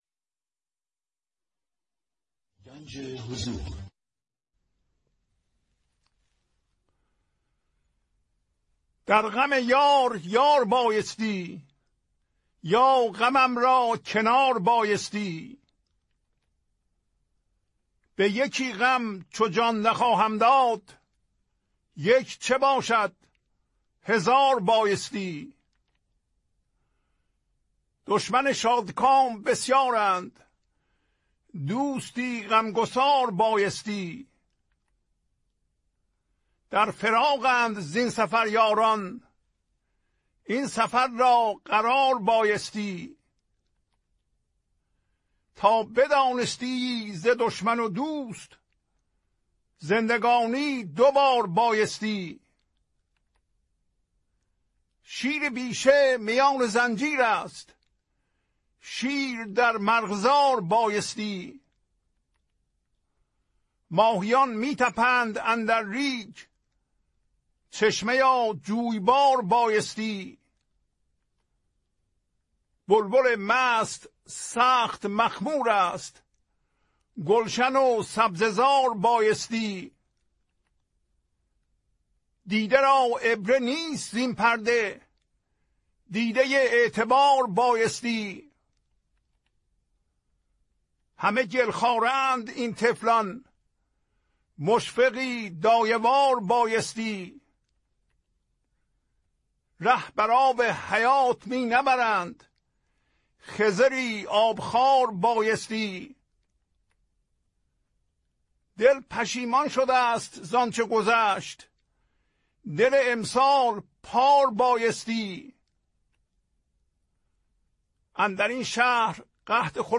خوانش تمام ابیات این برنامه - فایل صوتی
1019-Poems-Voice.mp3